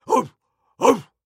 Мужчина имитирует лай пса